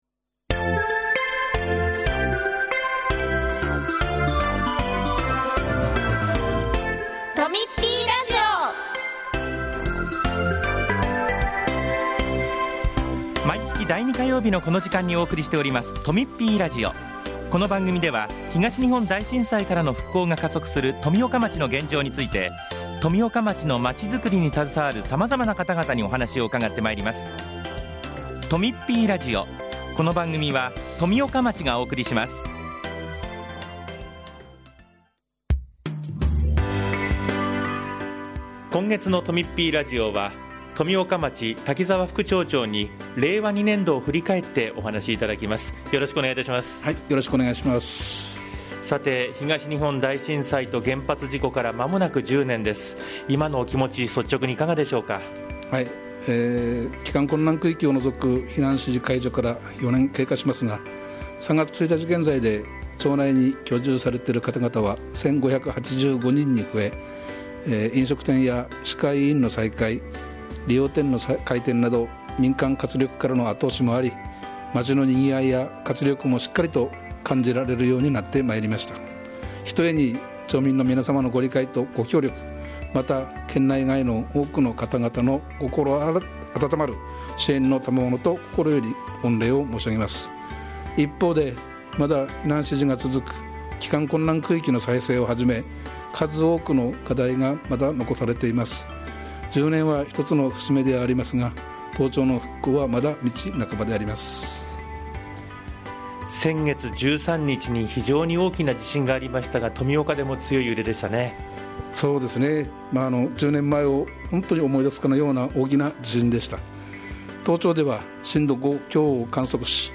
今回は、滝沢副町長が「今年度を振り返って」をテーマに話をしています。その他、町からのお知らせもあります。